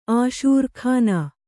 ♪ āṣūr khāna